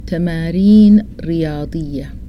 Sudanese Arabic Vocabulary List